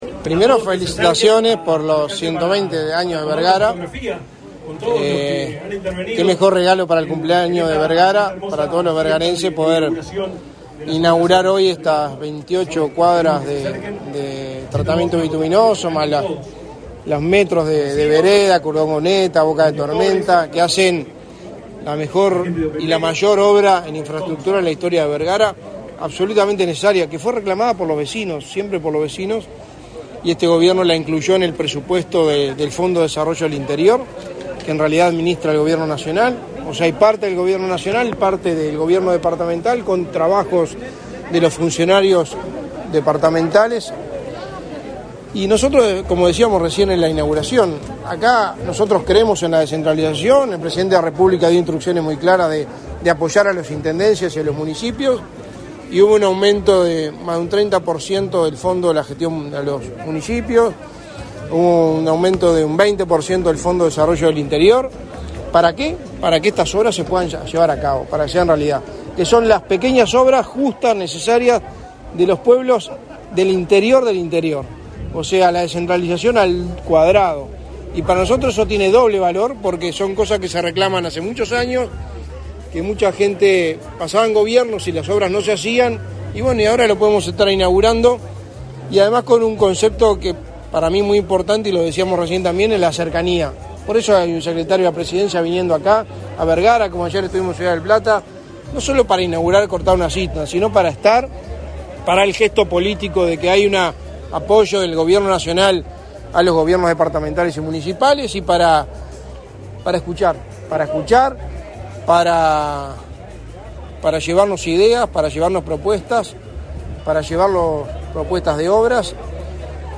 Declaraciones a la prensa del secretario de Presidencia, Álvaro Delgado
Tras participar en la inauguración de obras viales y pluviales en la localidad de Vergara, realizadas por la Oficina de Planeamiento y Presupuesto